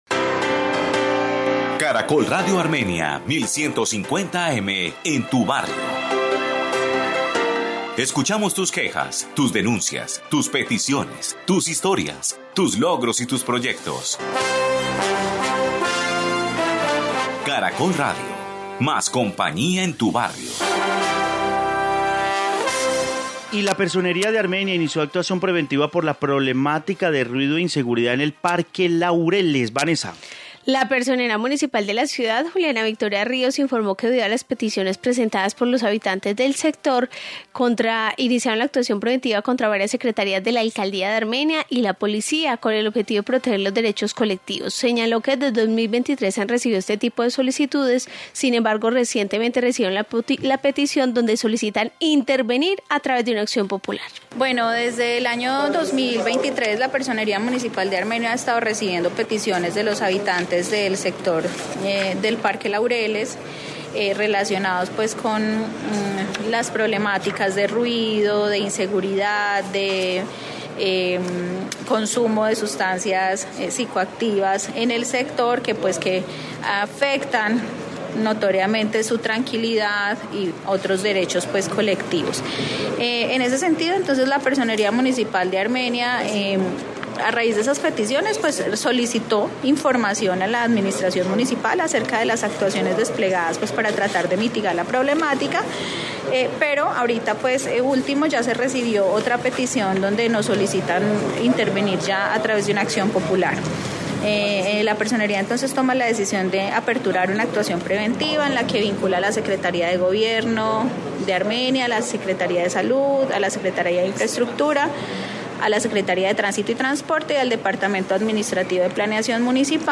Informe Personería de Armenia